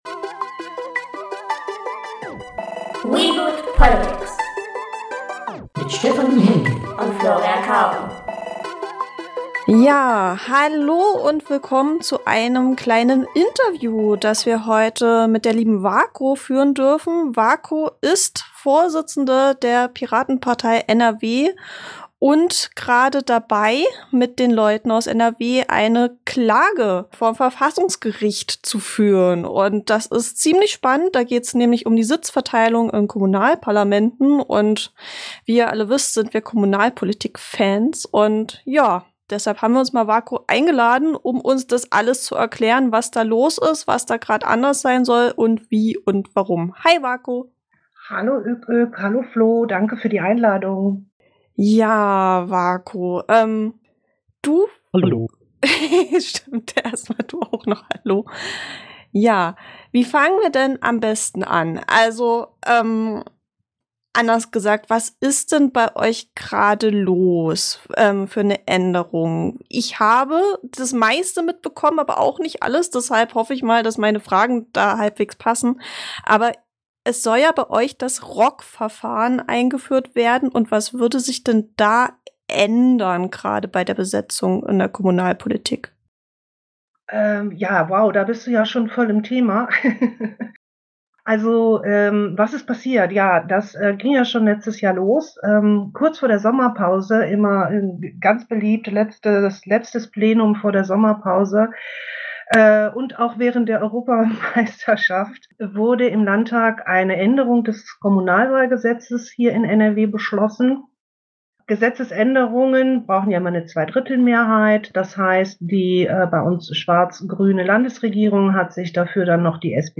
im Gespräch zu der geplanten Änderung der Sitzverteilung in nordrhein-westfälischen Kommunalparlamenten.